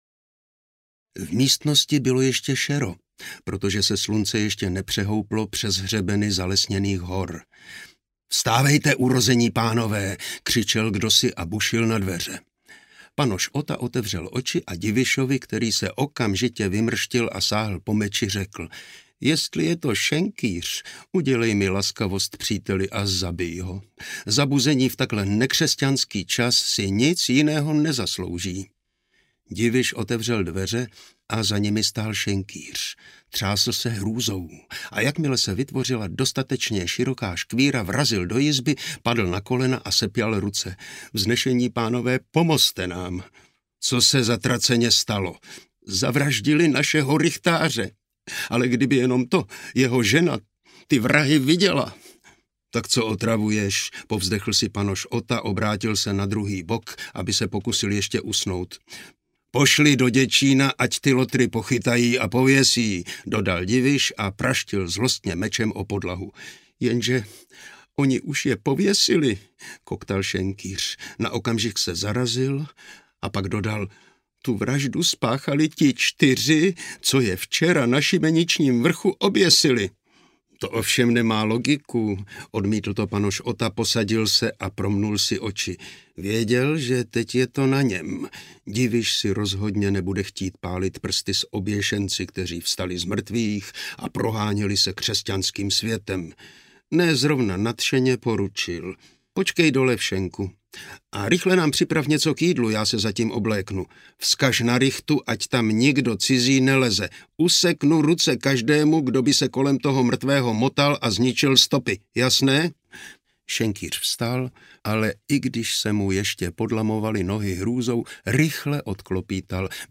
Spiknutí oběšenců audiokniha
Ukázka z knihy